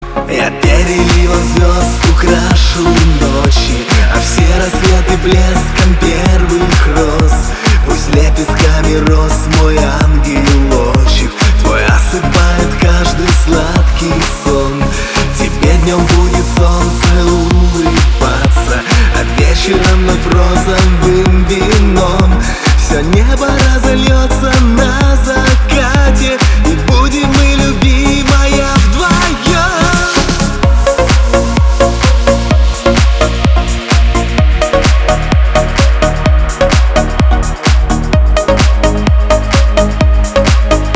• Качество: 320, Stereo
поп
мужской вокал
романтичные
русский шансон
танцевальные